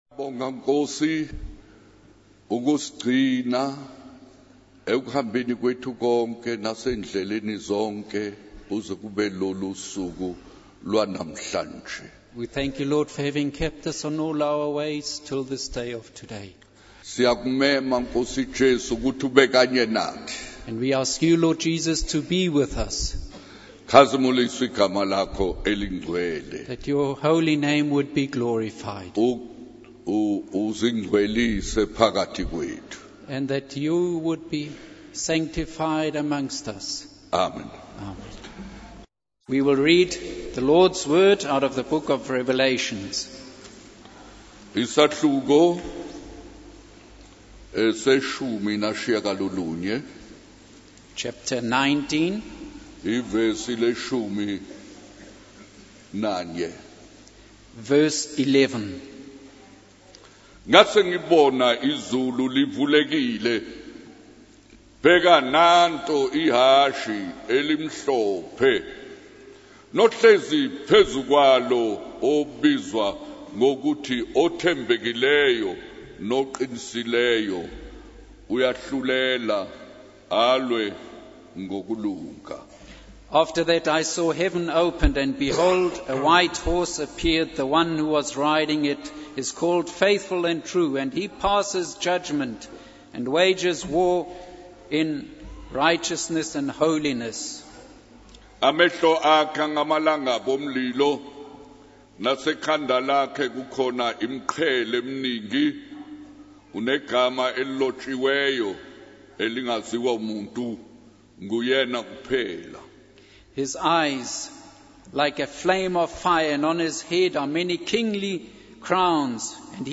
In this sermon, the speaker explores the secret of America's prosperity and investigates what makes the country work. He emphasizes the importance of seeking God above all else and being united in heart and mind.